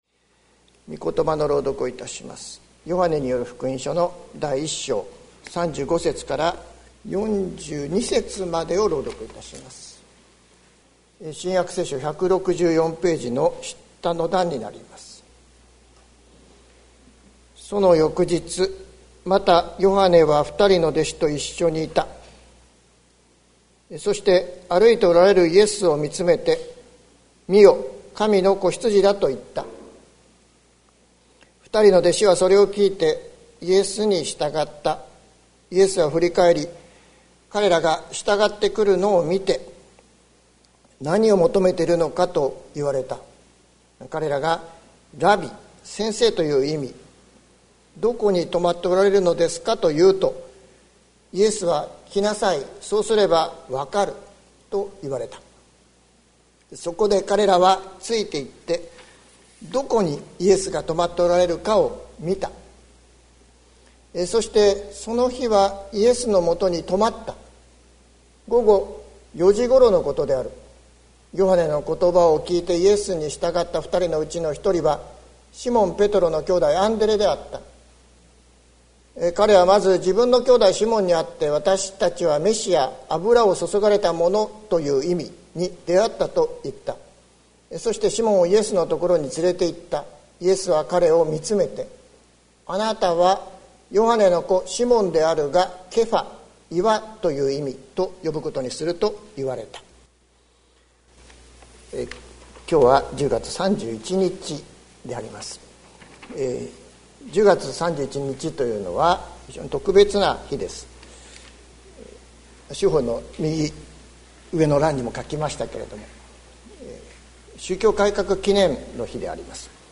2021年10月31日朝の礼拝「来て、見なさい」関キリスト教会
説教アーカイブ。